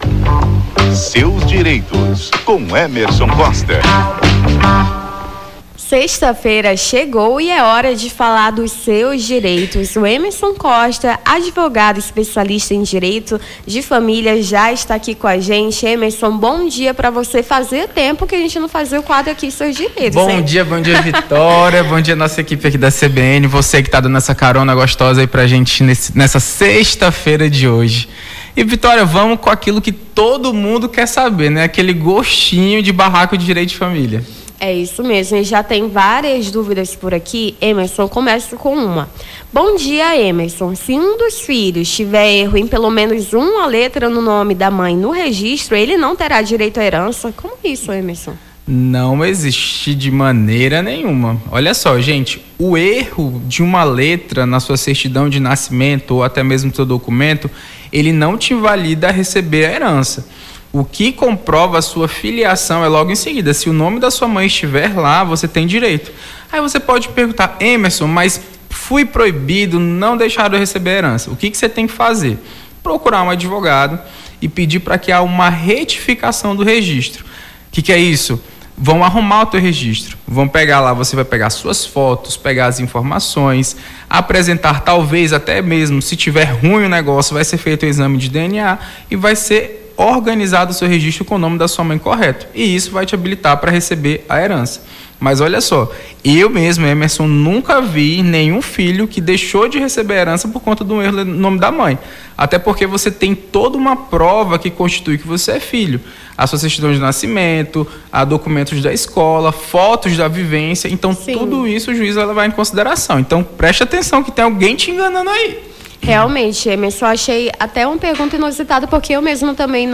Seus Direitos: advogado fala sobre direitos da família